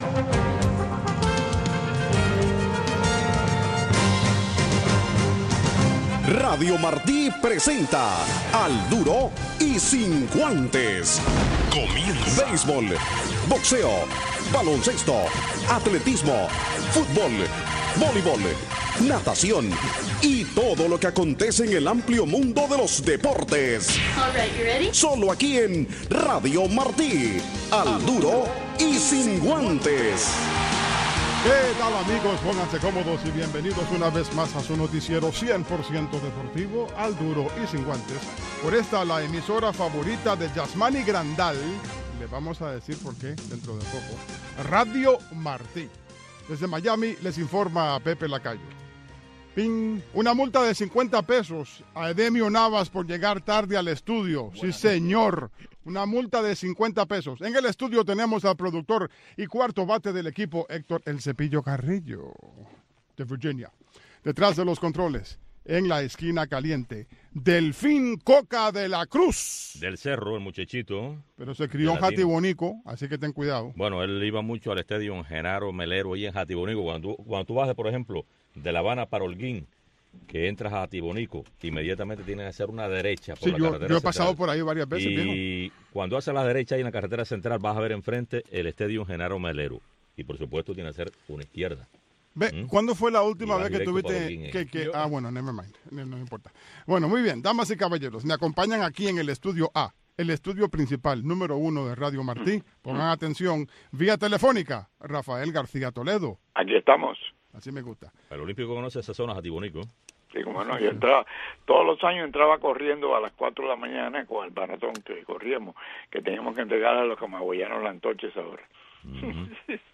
Noticiero Deportivo